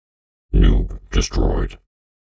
边疆2》机器人语音 " 侵略性增加 01
标签： 机器人的语音 语音 Borderlands2 机器人 加工 增加侵略 装载机 游戏人声 机器人 变形的 变形 科幻 Borderlands2 游戏声音
声道立体声